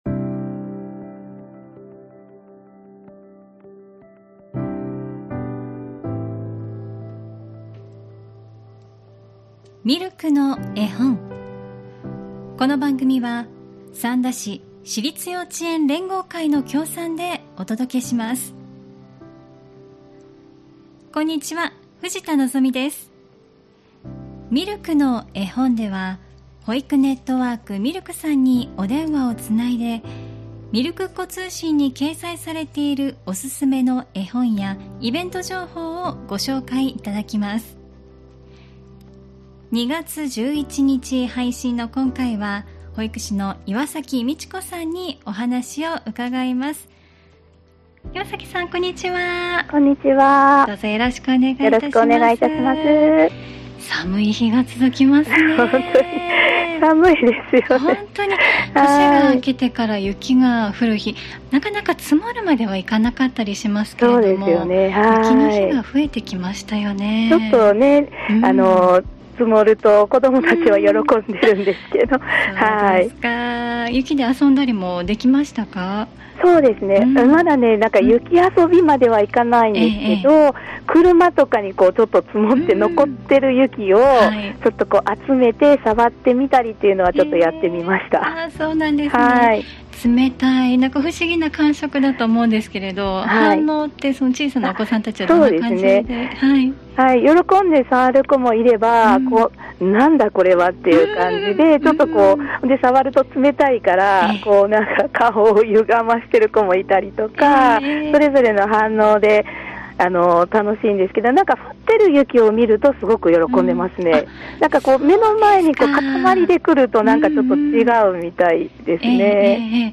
保育ネットワーク・ミルクの保育士さんにお電話をつないで、みるくっ子通信に掲載されているおすすめの絵本やイベント・施設情報などお聞きします。